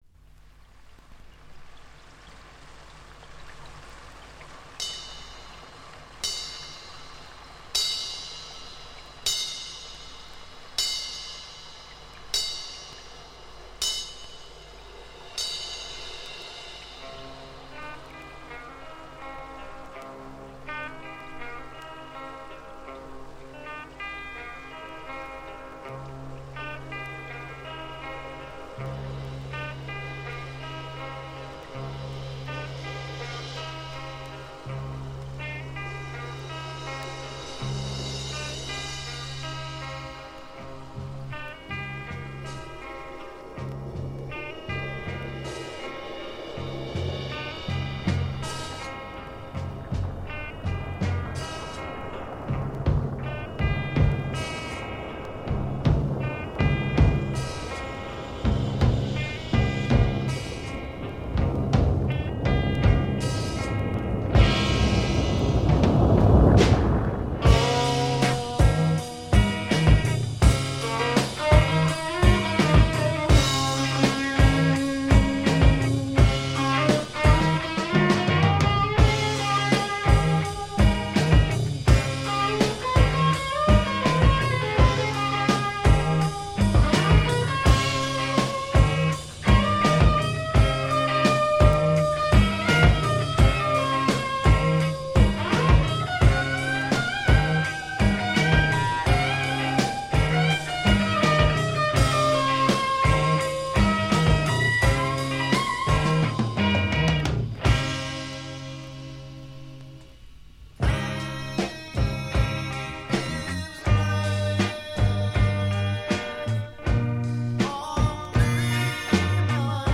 ヘヴィロックバンド
重たくブルージーでサイケデリックなサウンドが素晴らしいですね！